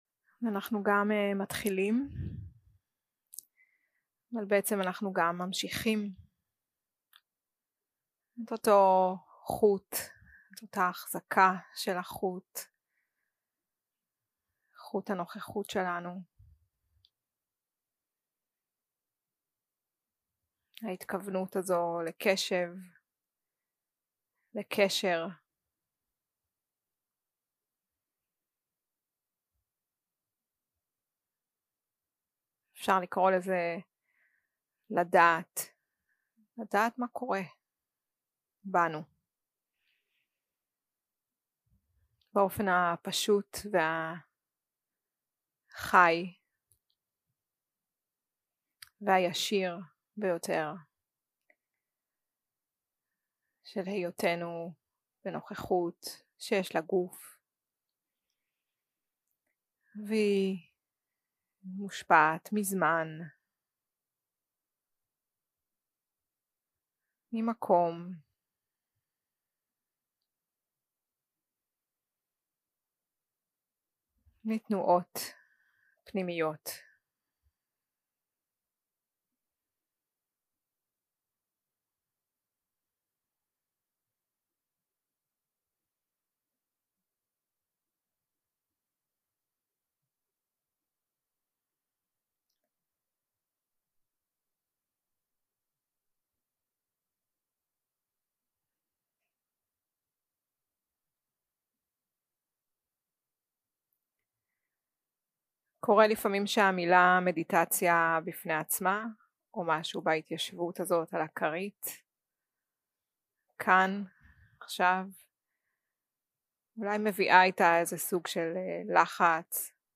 יום 2 – הקלטה 5 – צהריים – מדיטציה מונחית
Dharma type: Guided meditation